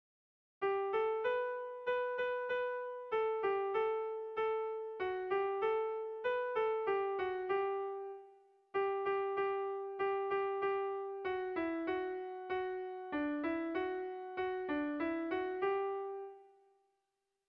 Eskeko kopla oso ezaguna
AB